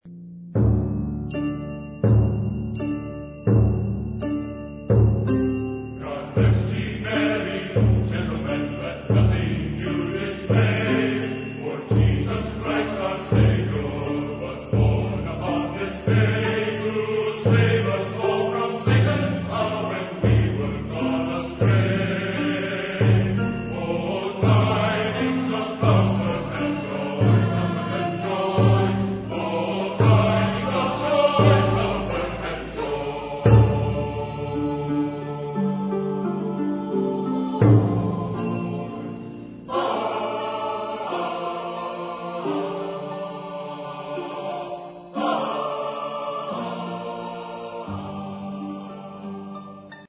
Genre-Style-Form: Sacred ; Christmas song ; Carol
Type of Choir: SSATBB  (6 mixed voices )
Tonality: modal